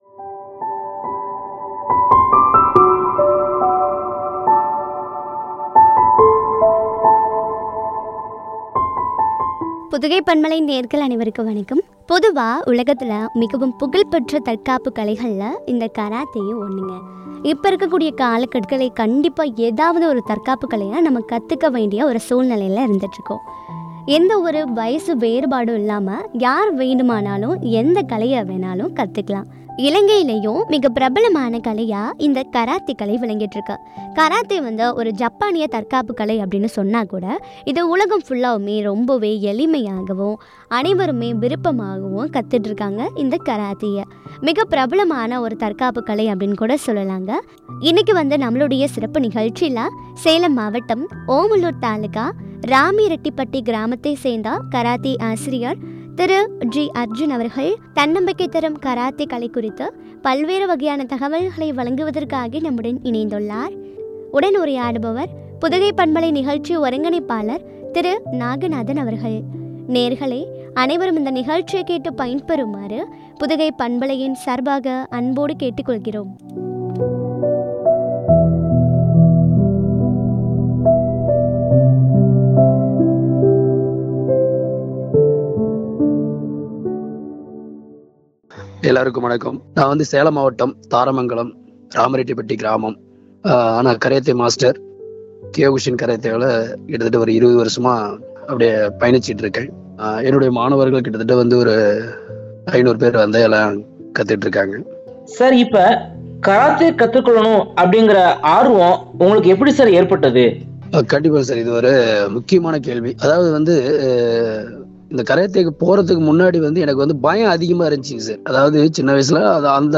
தன்னம்பிக்கை தரும் “கராத்தே” குறித்து வழங்கிய உரையாடல்.